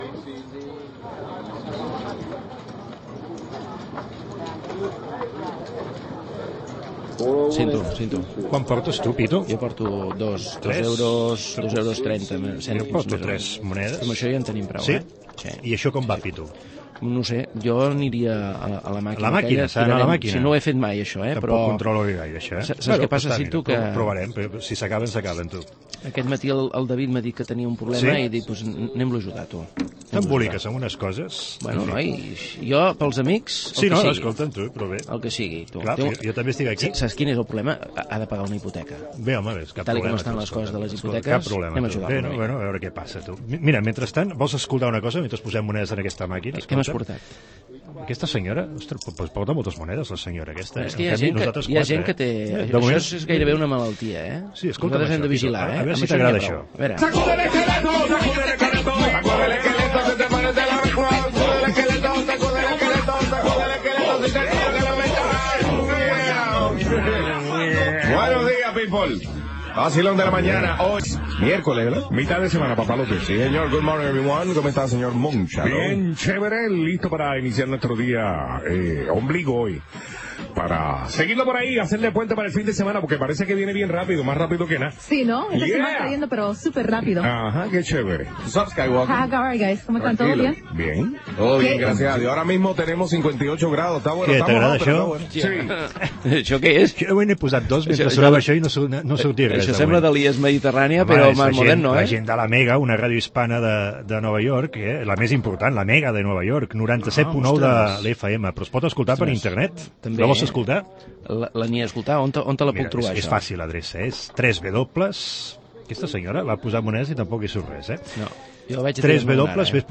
Ambient sonor en un casino amb enregistrament del programa "El vacilón de la mañana", de La Mega de Nova York, i un comentari sobre el discurs oral.
Divulgació